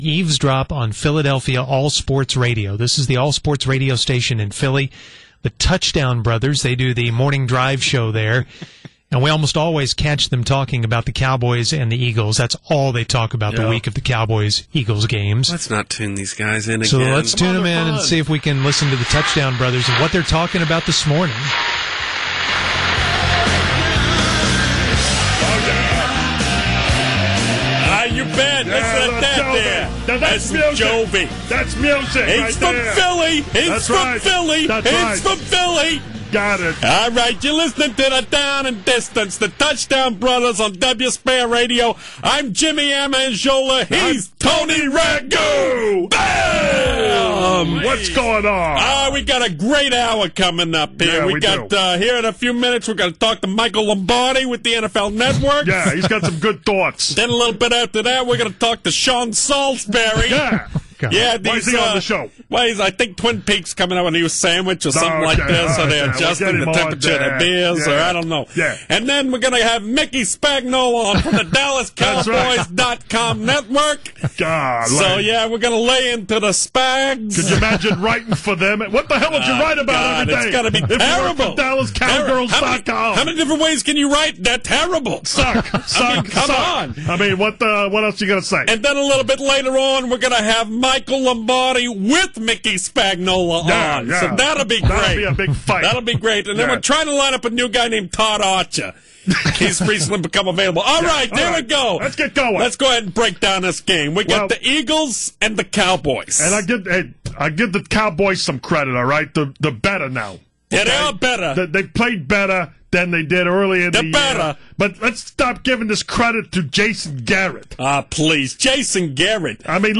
That annoying show from Philly was listened in to this morning by the Musers.